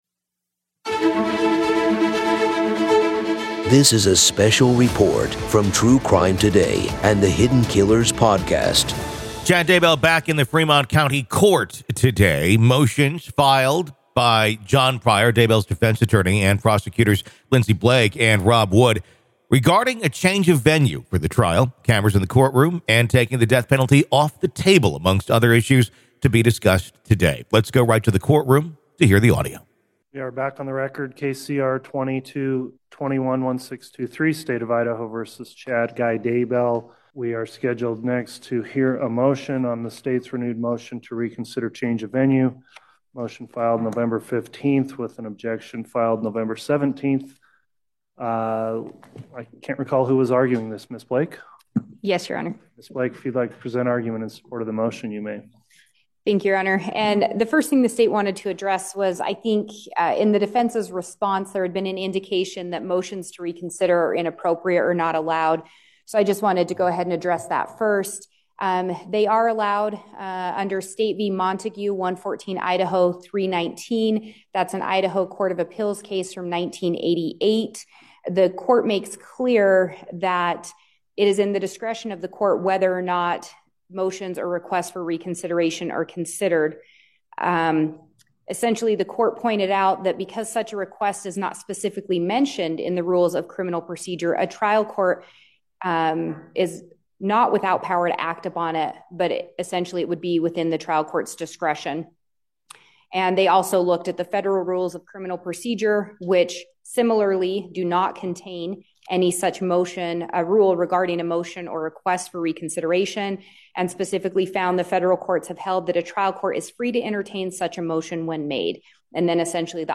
RAW COURT AUDIO-‘Doomsday Cult’ Prophet Murder Trial — ID v. Chad Daybell - Hearing Part 3